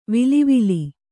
♪ vili vili